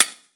darkwatch/client/public/dice/sounds/surfaces/surface_metal6.mp3 at d543b173b41daf467b594069de77d073568c1e79
surface_metal6.mp3